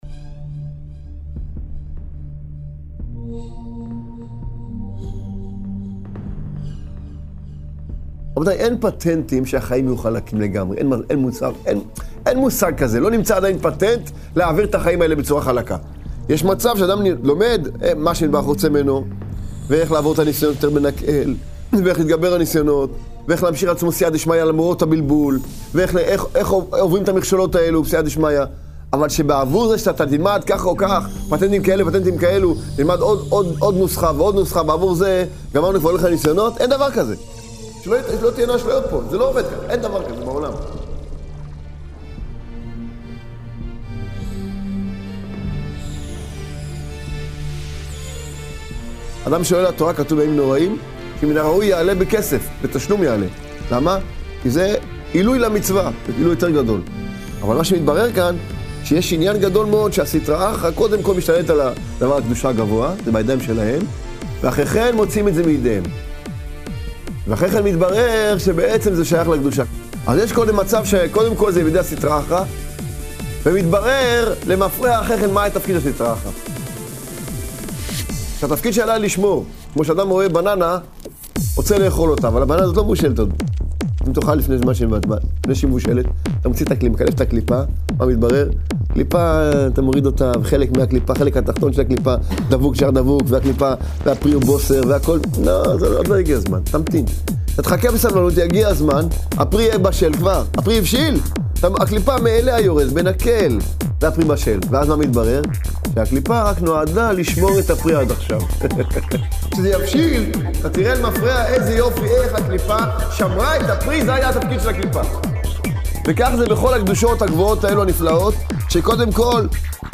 שיעורי שמע